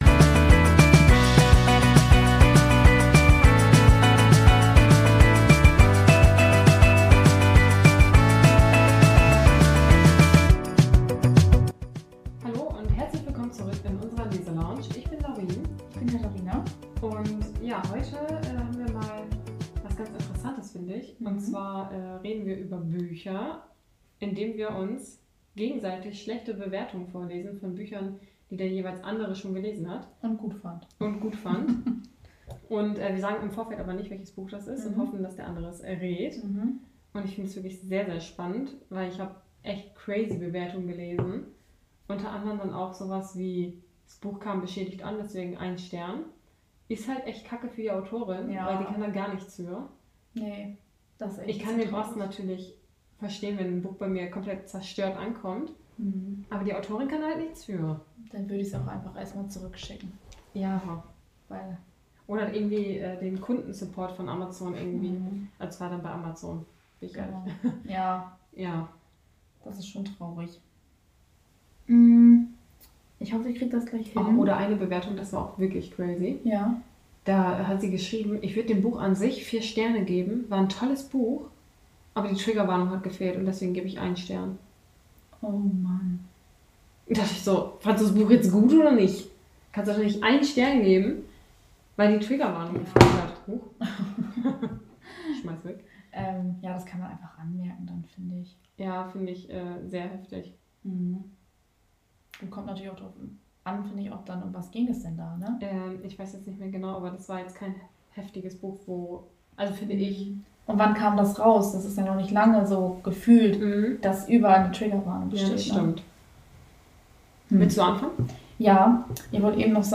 In der heutigen Folge quälen wir uns ein bisschen und lesen uns gegenseitig schlechte Bewertungen von unseren Lieblingsbüchern vor.